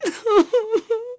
Aselica-Vox_Sad.wav